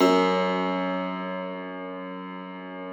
53k-pno04-F0.wav